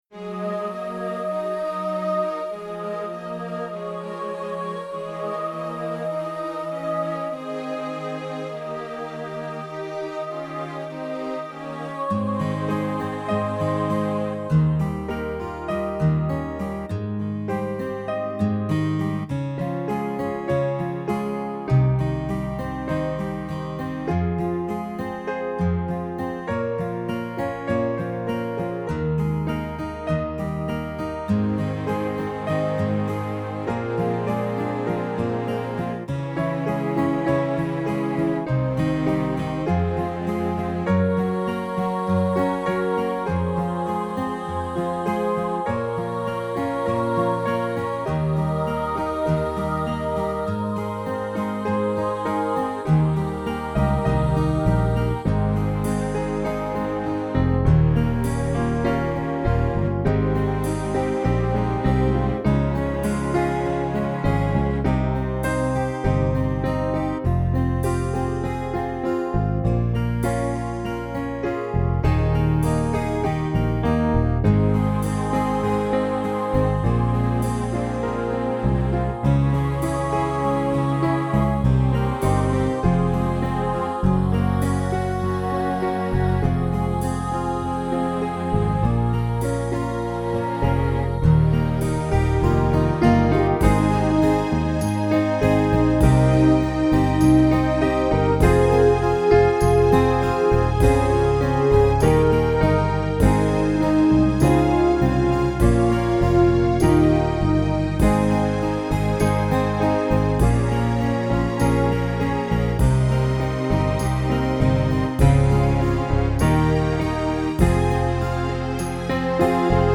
RAINBOW THROUGH MY TEARS Karaoke in Progress
rainbow-midi.mp3